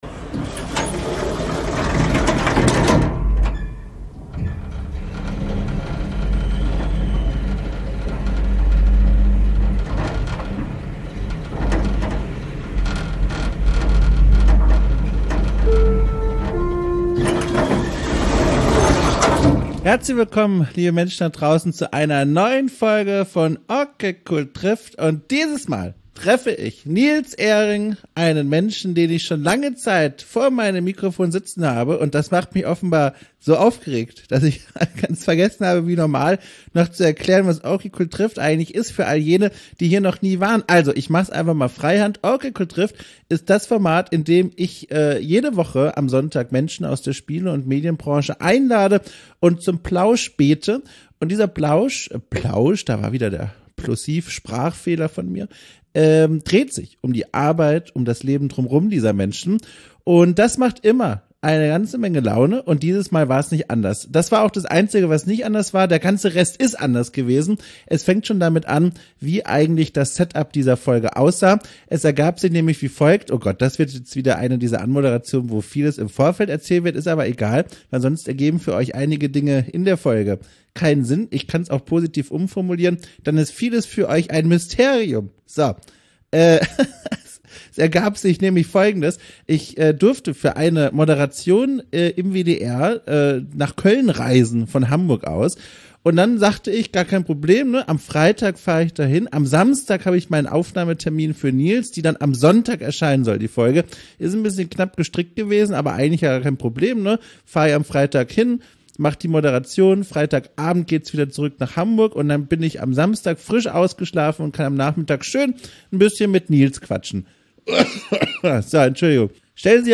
# Interviews